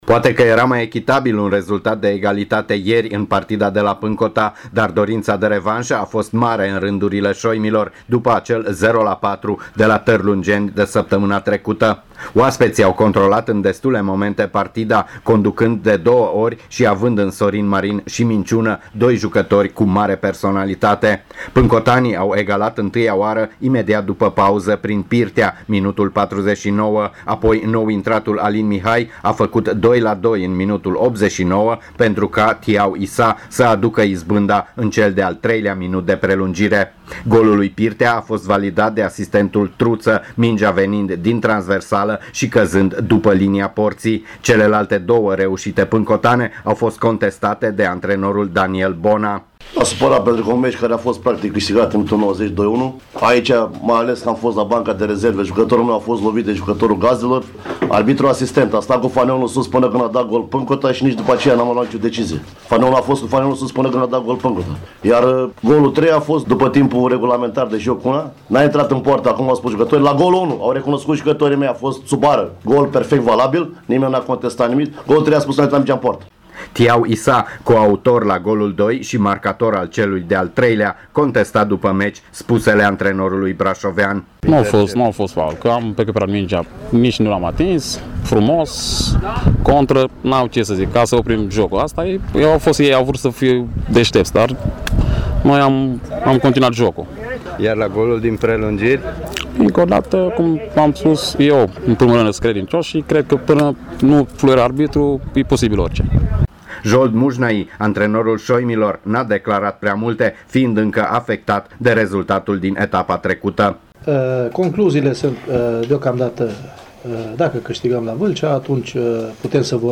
Cel mai spectaculos meci din grupa de jos s-a jucat la Pâncota, unde gazdele au învins cu 3-2, după ce au fost conduse în două rânduri; ascultaţi reportajul
5-reportaj-Pancota-Tarlungeni.mp3